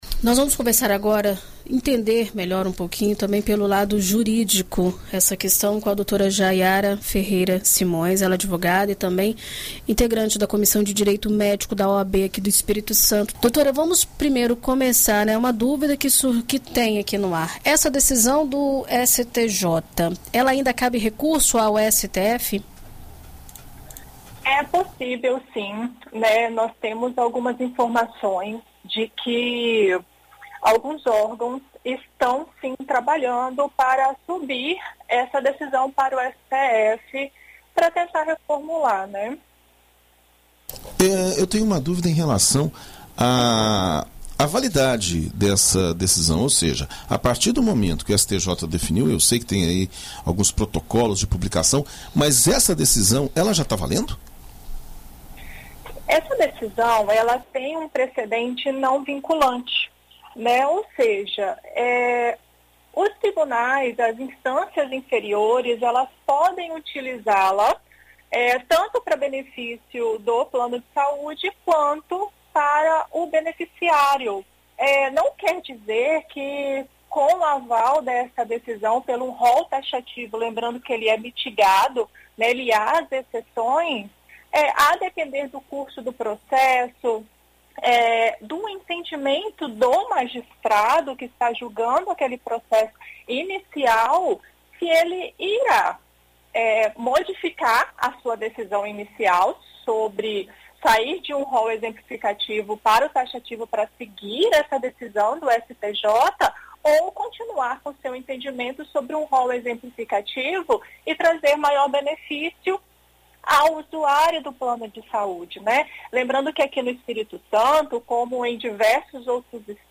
ENT-ADVOGADA-ROL-ANS.mp3